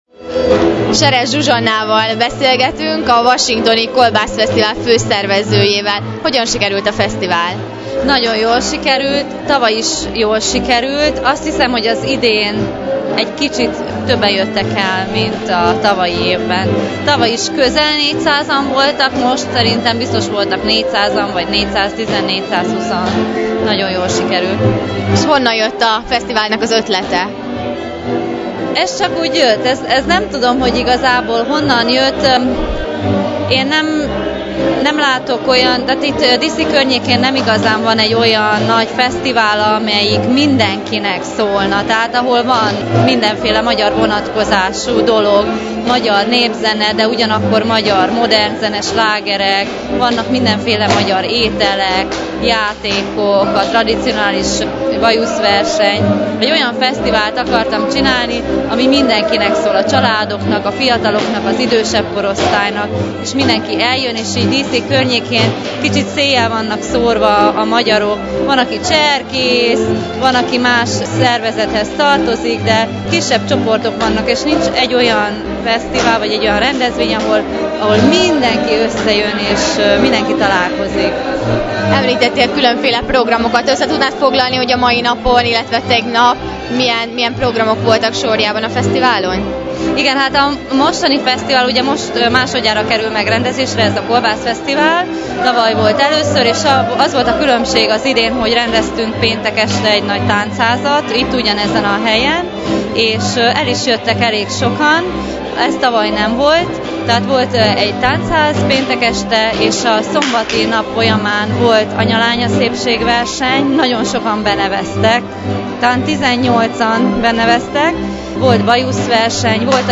Beszámoló a washingtoni kolbászfesztiválról – Bocskai Rádió